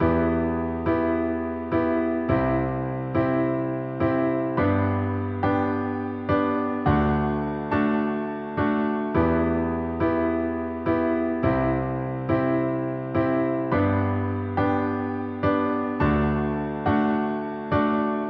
决赛钢琴
描述：{键Ab}(我想)
标签： 105 bpm Hip Hop Loops Piano Loops 3.08 MB wav Key : A
声道立体声